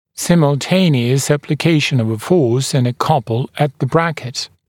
[ˌsɪml’teɪnɪəs ˌæplɪ’keɪʃn əv ə fɔːs ənd ə ‘kʌpl ət ðə ‘brækɪt][ˌсимл’тэйниэс ˌэпли’кейшн ов э фо:с энд э ‘капл эт зэ ‘брэкит]одновременное приложение силы и пары сил к брекету